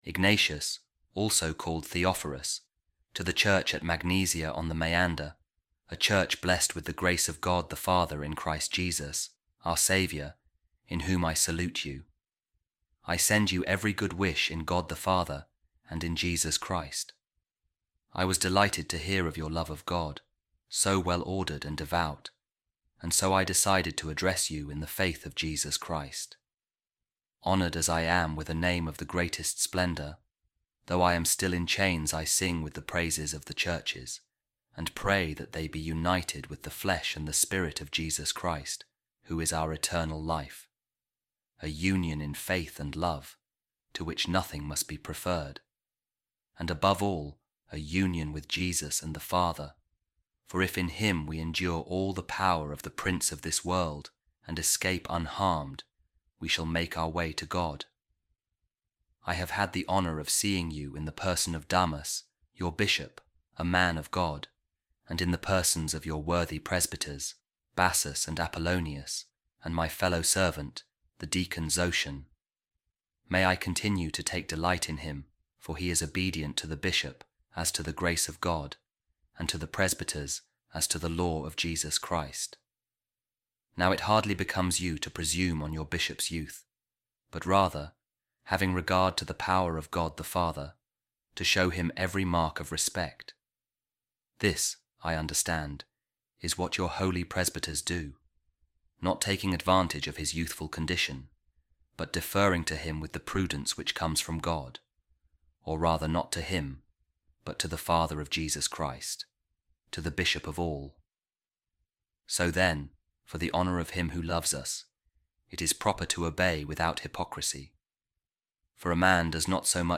A Reading From The Letter Of Saint Ignatius Of Antioch To The Magnesians | We Must Live The Name Of Christians